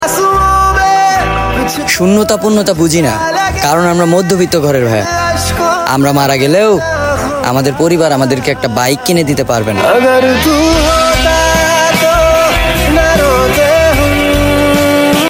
akta bike sound effects free download